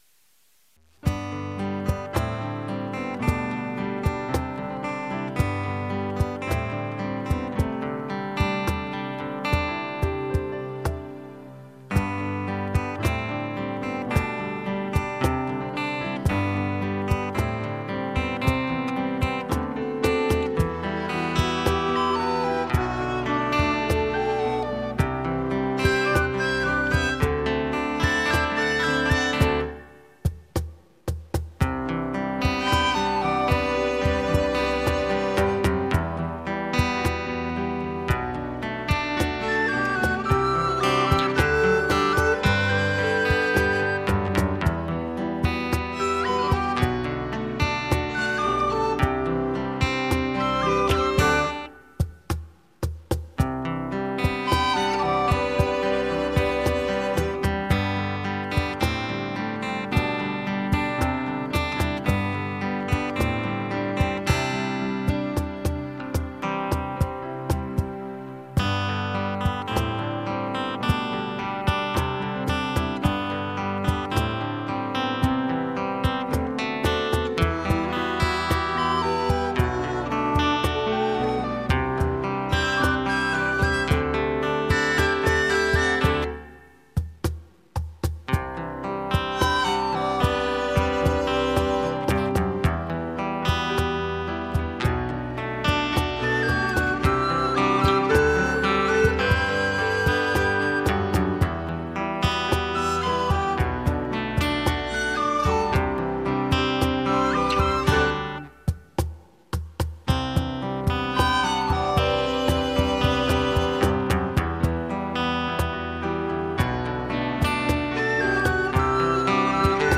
Zároveň si pesničky môžete stiahnuť ako mp3, niektoré aj v dvoch verziách, buď len samostatnú melódiu, alebo aj so spevom.